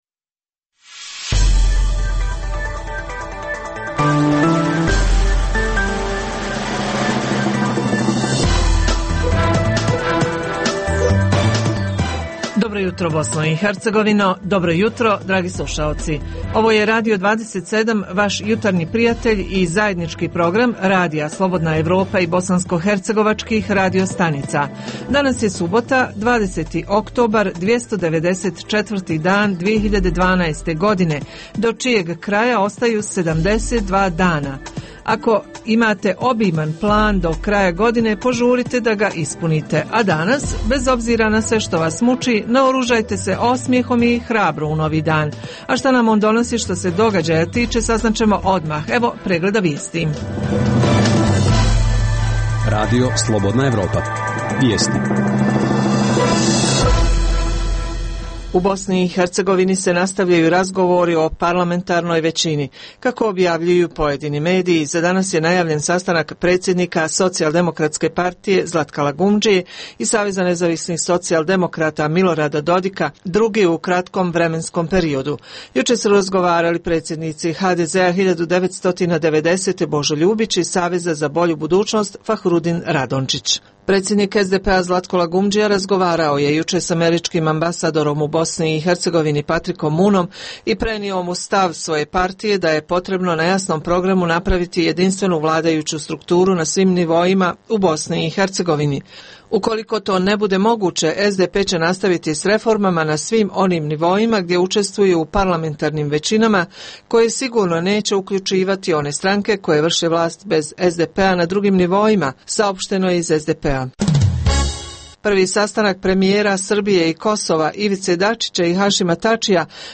- „Živa javljanja“ reportera iz više gradova - Koji su razlozi za vakcinaciju protiv sezonske gripe?
- Uz tri emisije vijesti, slušacima nudimo i „jutarnji izbor“ muzike.